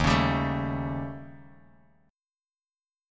D#7b5 chord